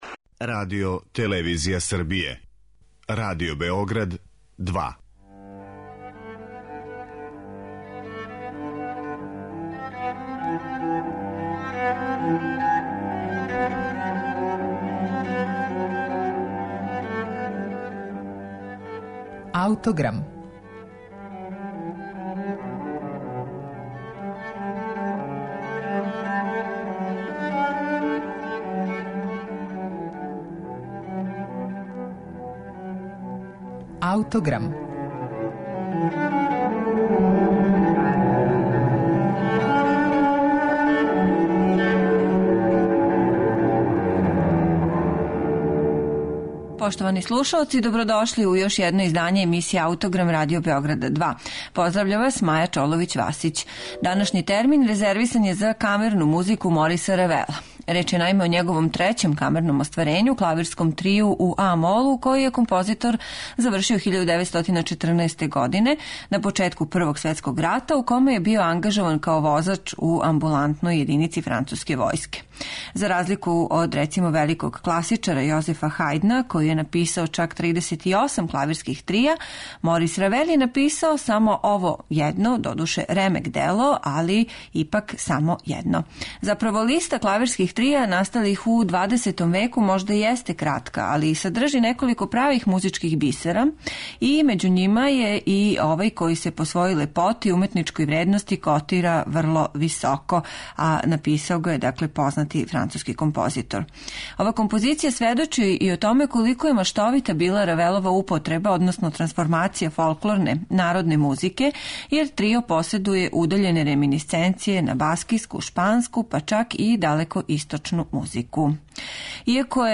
У данашњем 'Аутограму' слушаћете клавирски трио француског композитора Мориса Равела.